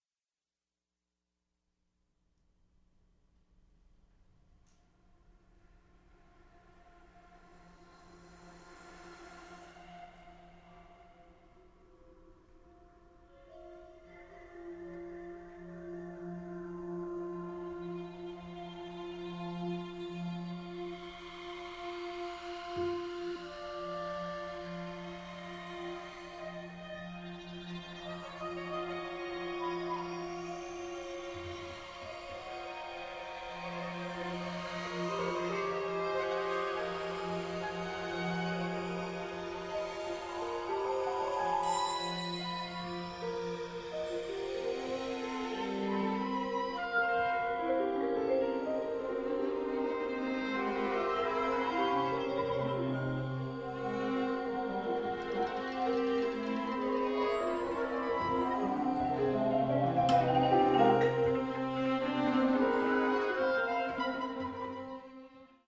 ” a work for instrumental ensemble and electronics.